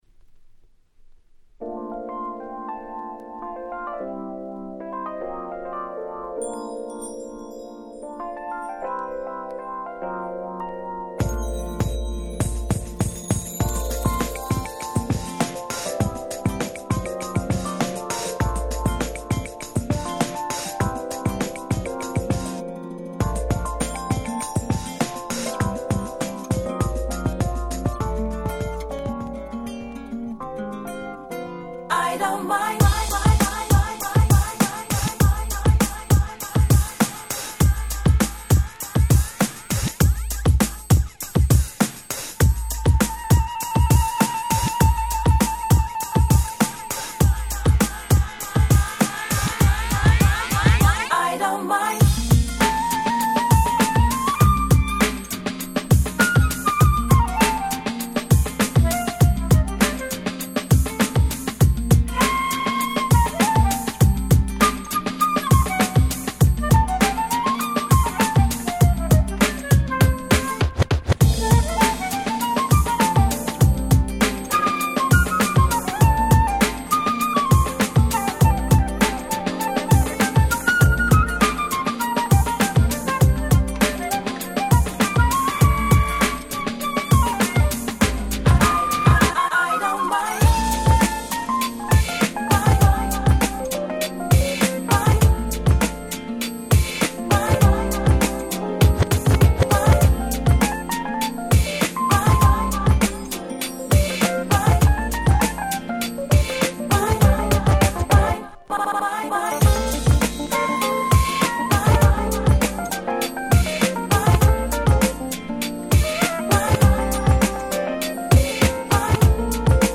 99' Monster Hit UK R&B !!!!!